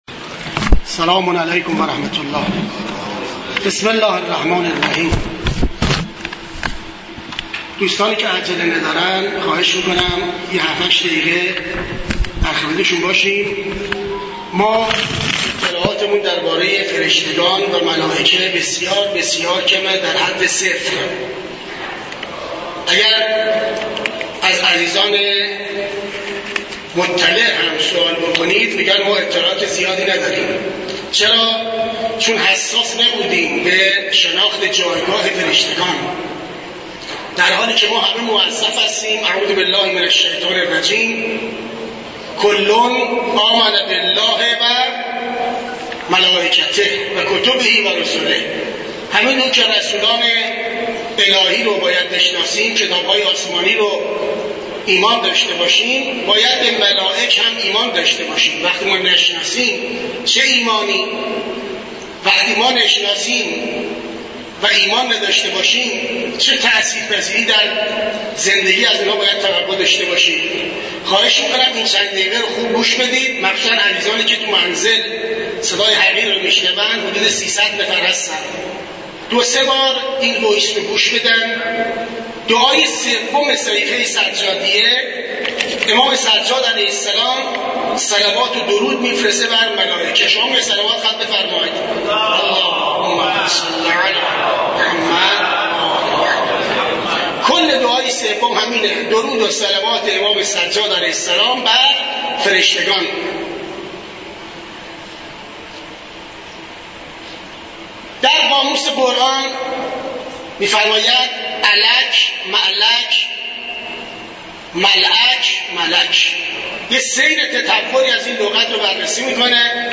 سخنرانی
در شرح دعای ۳ صحیفه سجادیه در مسجد دانشگاه کاشان برگزار گردید.